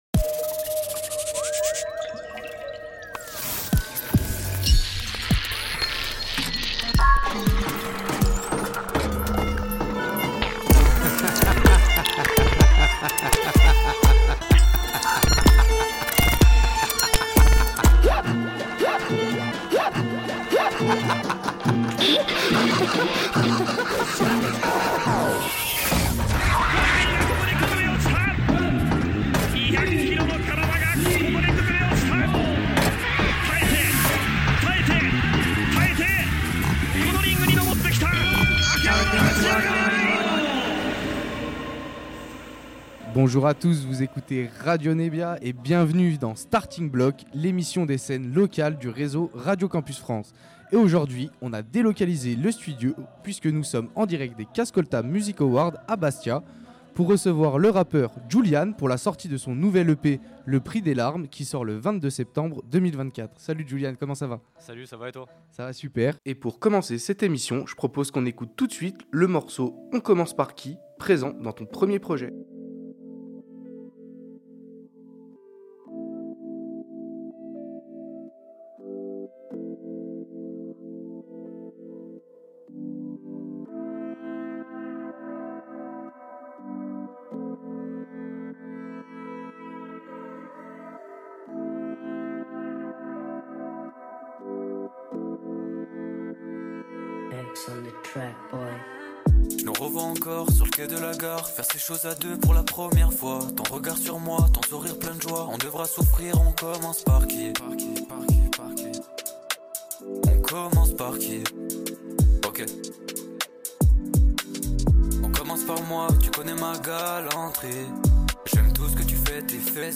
Radio Nebbia s'est rendu à Bastia à l'occasion des K'Scolta Music Awards